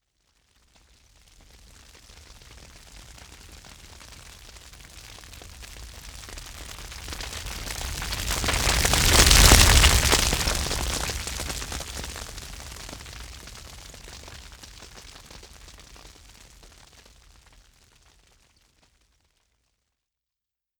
Stereo audio example. Fire sound moving across stereophonic field at 16 mph.